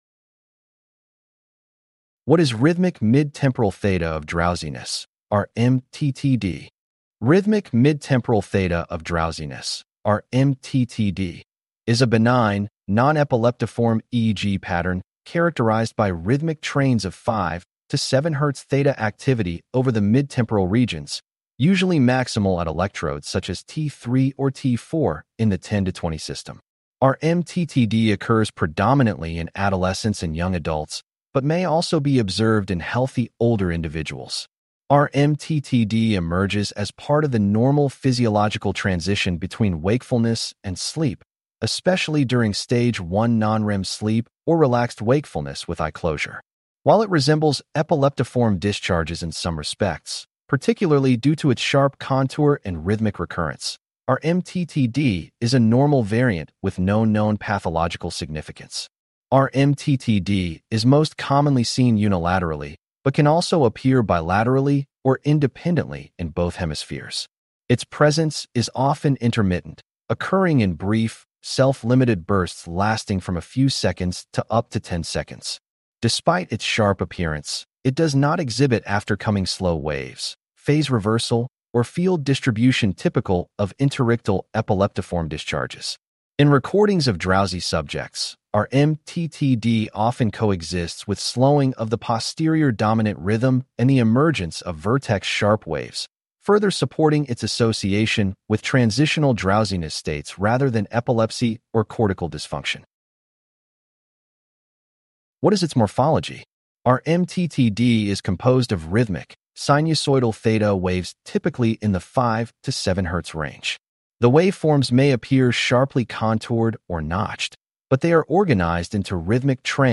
CLICK TO HEAR THIS POST NARRATED RMTTD occurs predominantly in adolescents and young adults but may also be observed in healthy older individuals.